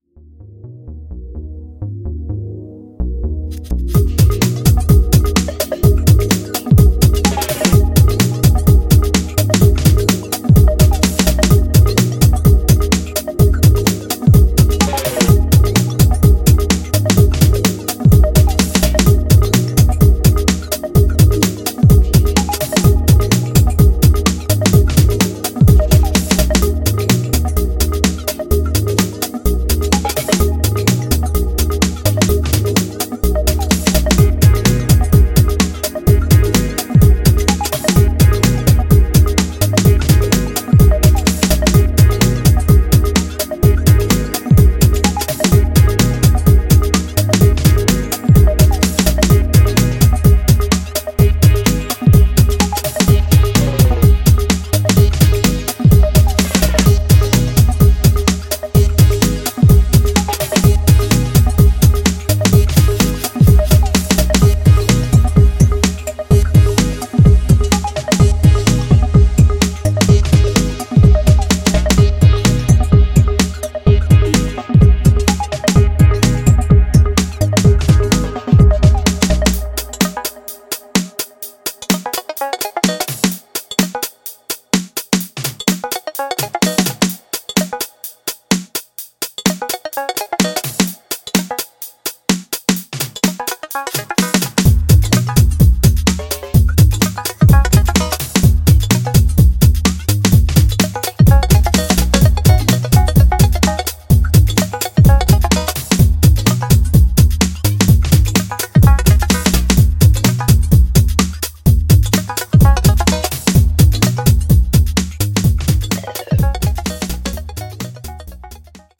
channels deep, chugging house,